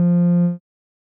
Techmino/media/sample/bass/21.ogg at beff0c9d991e89c7ce3d02b5f99a879a052d4d3e
添加三个简单乐器采样包并加载（之后用于替换部分音效）